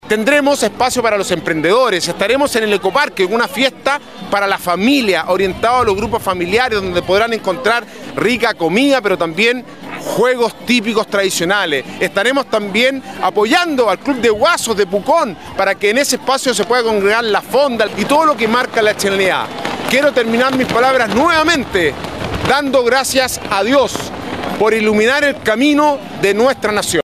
Alcalde-Sebastian-Alvarez-actividades-del-18-en-la-comuna-.mp3